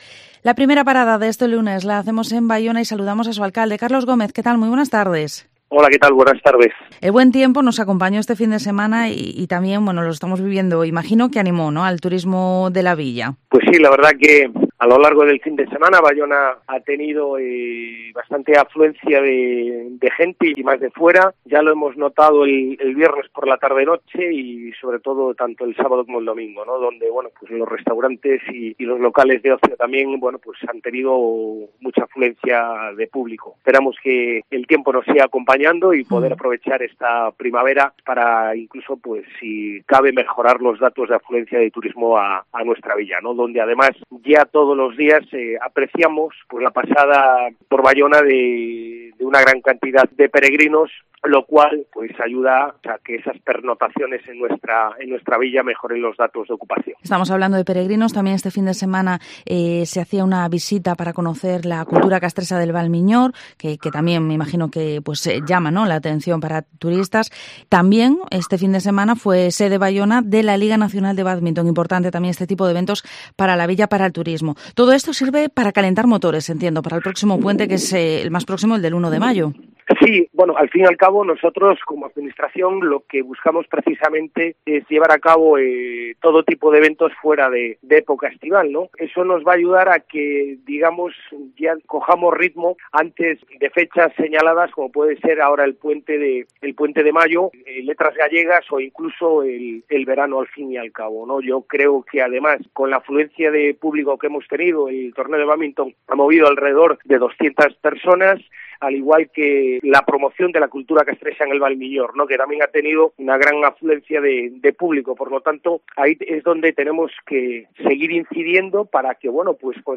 Entrevista al Alcalde de Baiona, Carlos Gómez